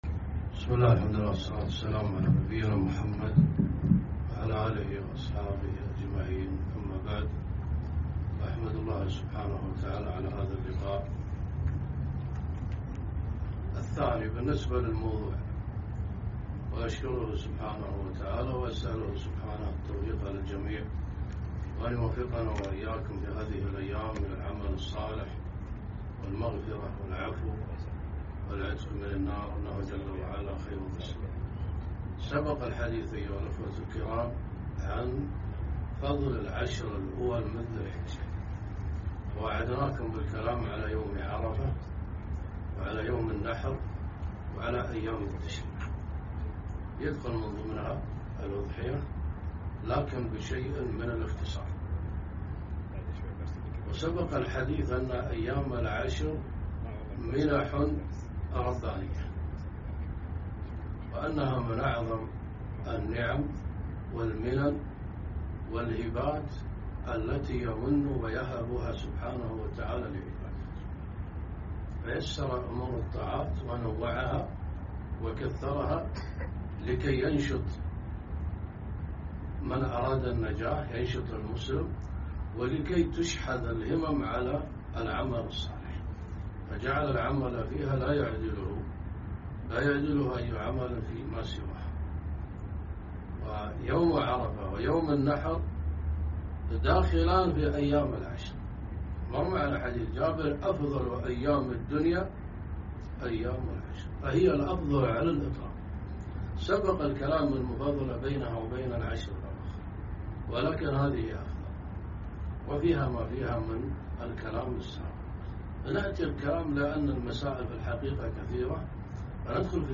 محاضرة - فضل يوم عرفة والنحر وأيام التشريق وأحكام الأضحية